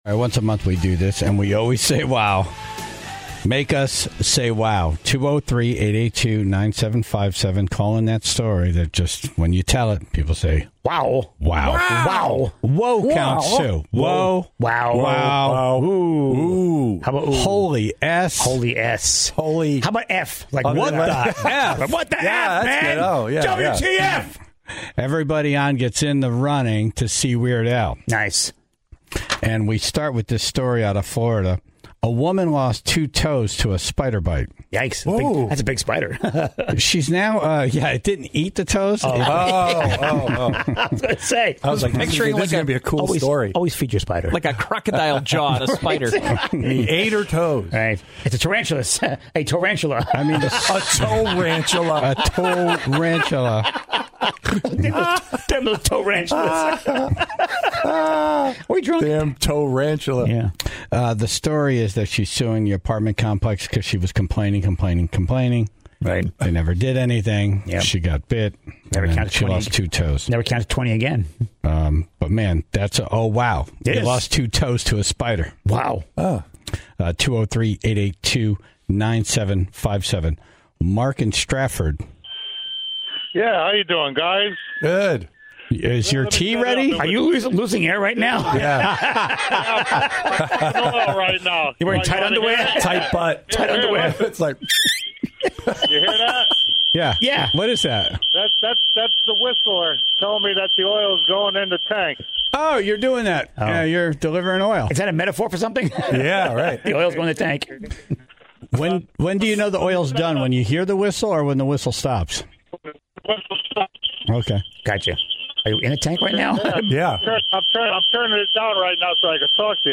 Immediately, stories of cars flipping, and losing fingers were called in. The segment ended with an eyewitness account of a horrifically violent scene.